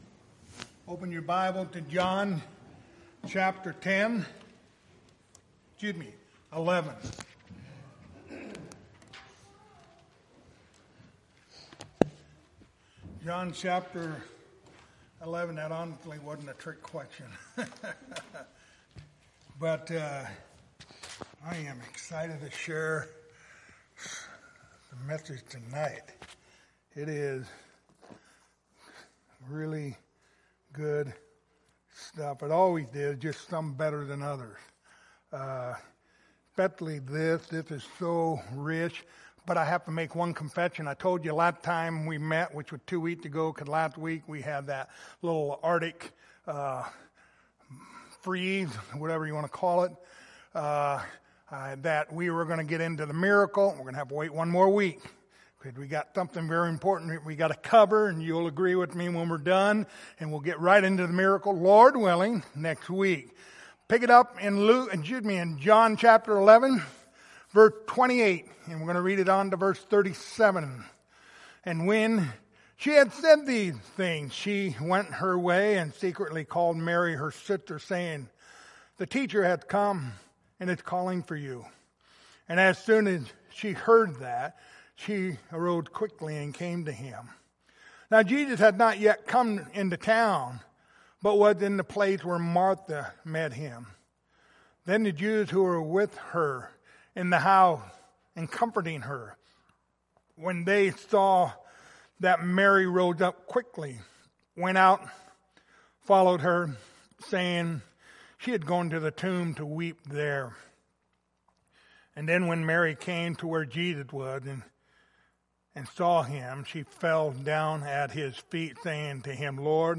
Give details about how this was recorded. Passage: John 11:28-37 Service Type: Wednesday Evening